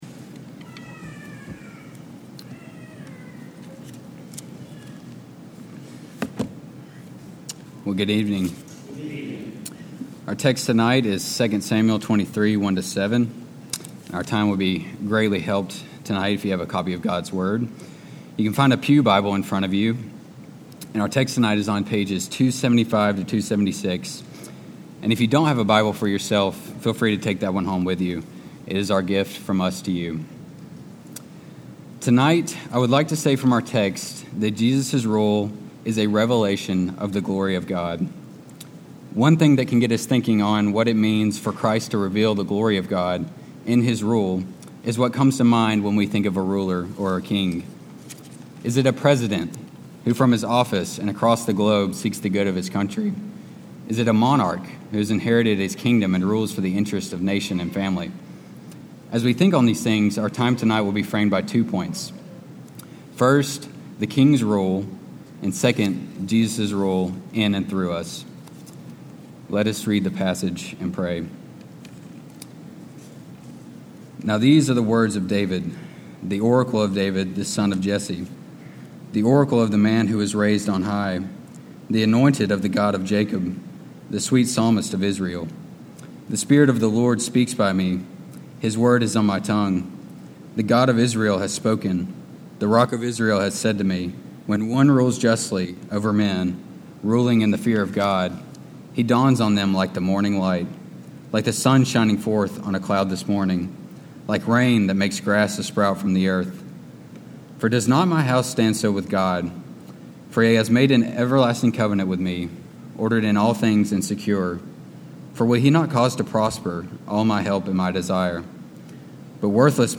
Sermon-811-Even.mp3